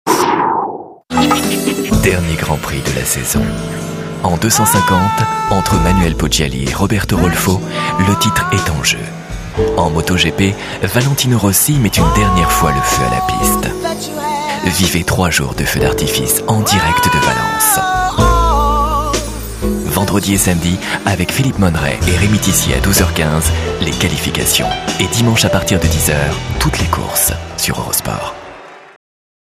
EUROSPORT formule 1 - Comédien voix off
Genre : voix off.